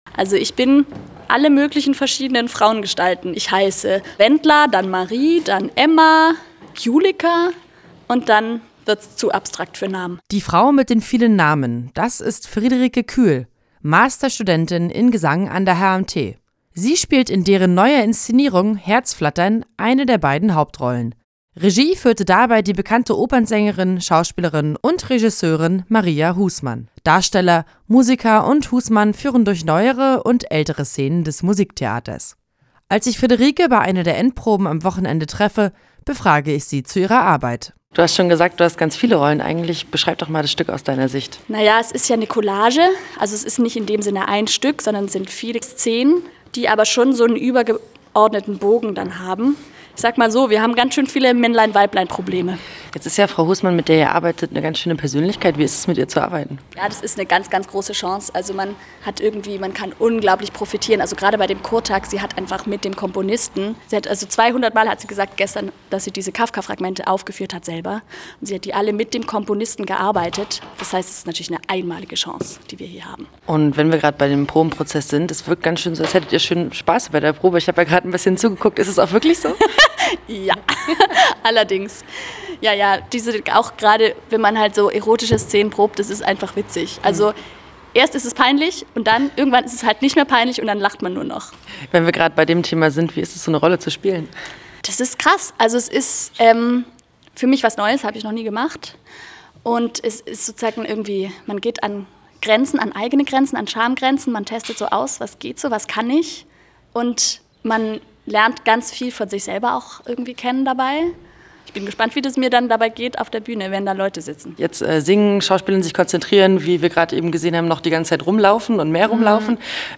Thematisch geht es um Liebe und die Beziehung zwischen zwei Menschen – ein bisschen Erotik ist auch dabei. Wir waren bei einer Probe und haben bei der Besetzung nachgefragt: